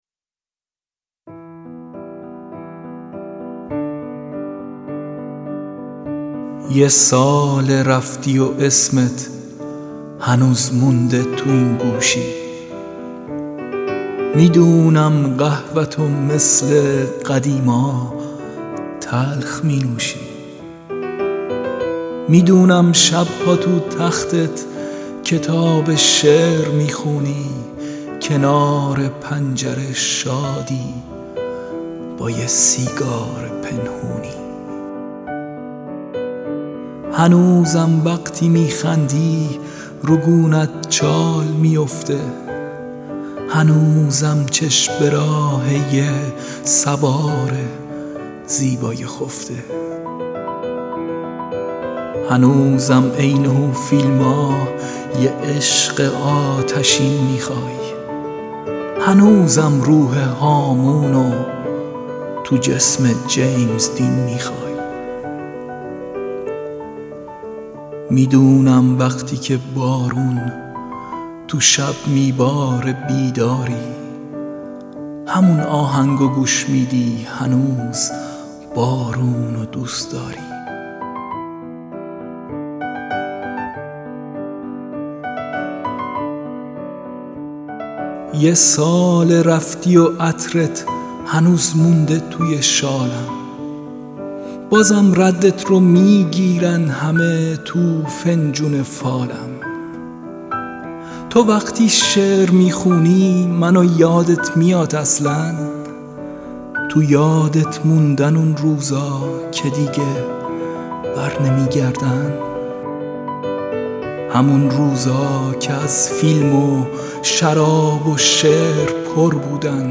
دانلود دکلمه هنوز بارون و دوست داری یغما گلرویی همراه با متن
گوینده :   [یغما گلرویی]